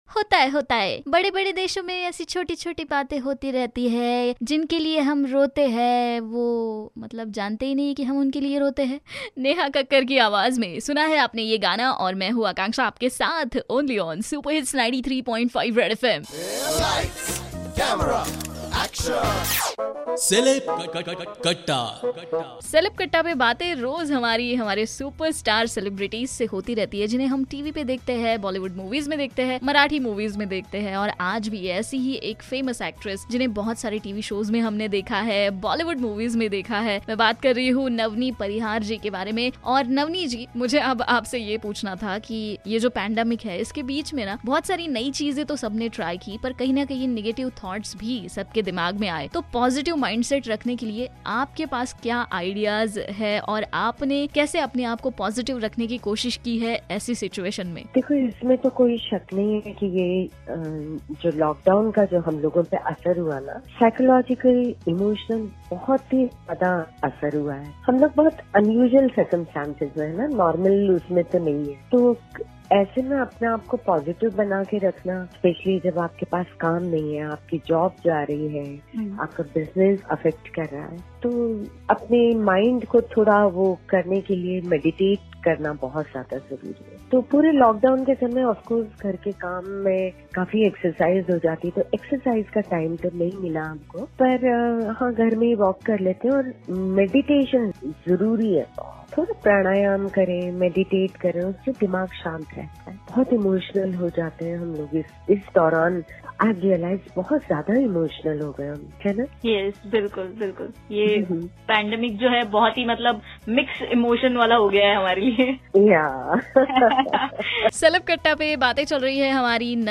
In this interview she gave some ideas for positive mindset.